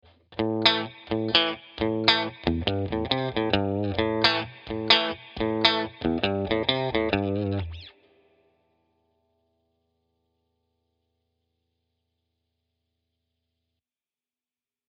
Adding Vibrato
One last technique that we’ll look into here is vibrato technique, which we’ll add to the last note of the riff.